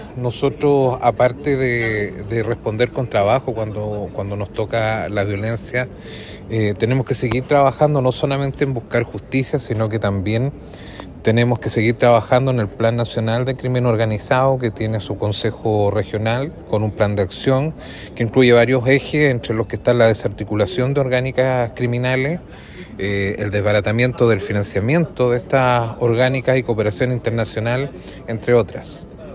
El seremi de Seguridad en La Araucanía, Israel Campusano, junto con lamentar la edad de los involucrados en el hecho, dijo que el gobierno debe seguir trabajando en los protocolos para enfrentar el crimen organizado.